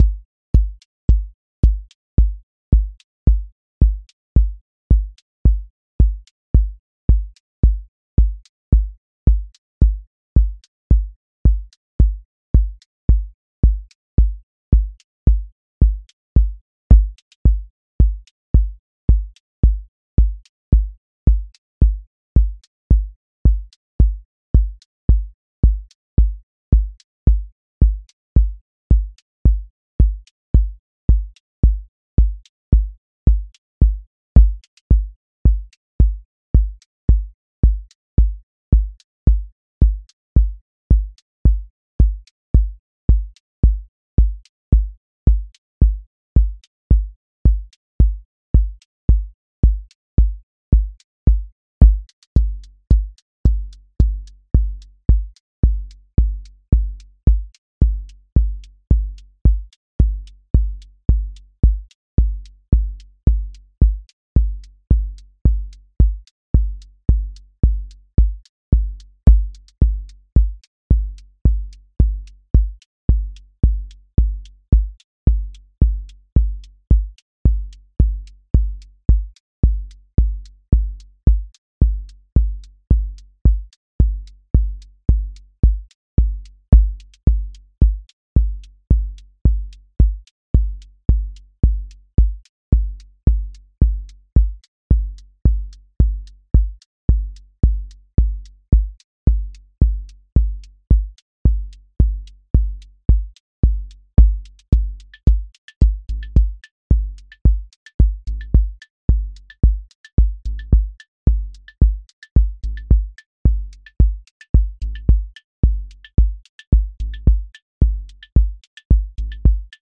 QA Listening Test trance Template: four_on_floor
120-second house song with grounded sub, counter motion, a bridge lift, and a clear return
• macro_house_four_on_floor
• voice_kick_808
• voice_hat_rimshot
• voice_sub_pulse
• motion_drift_slow
• tone_warm_body